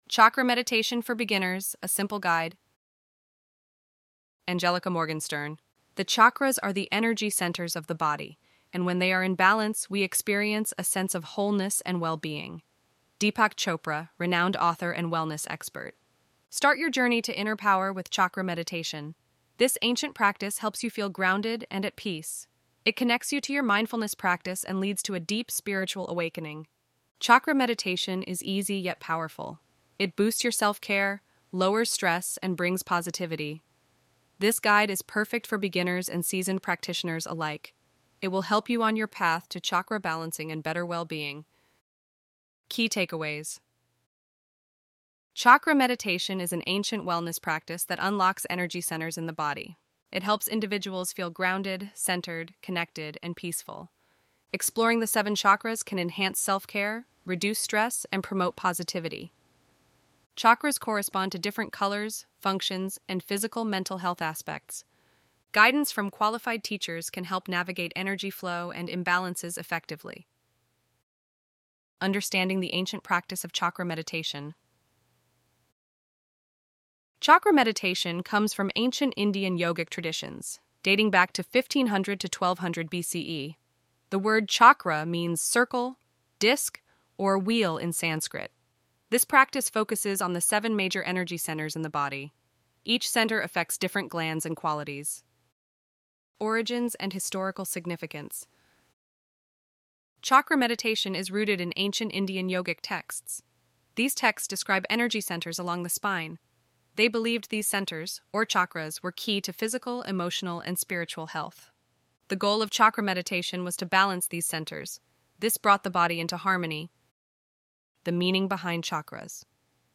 ElevenLabs_Chakra_Meditation_for_Beginners_A_Simple_Guide.mp3